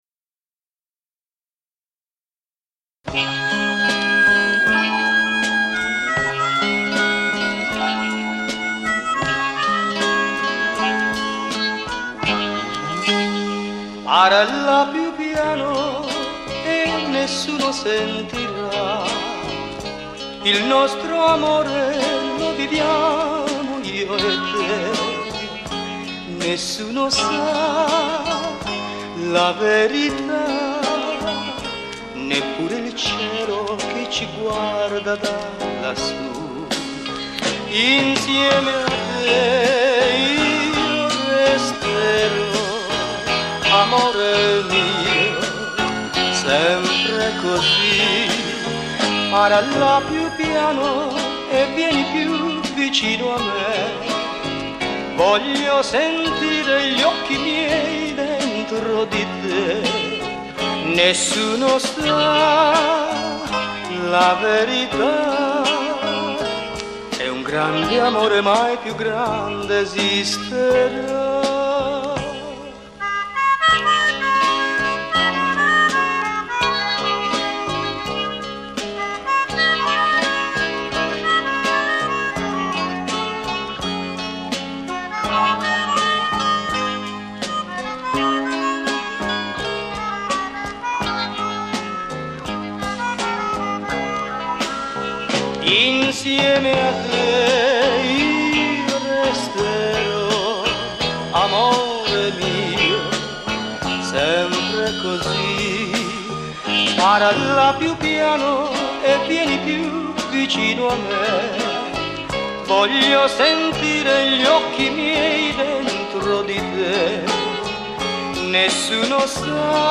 DRUMS
GUITARS
TASTIERE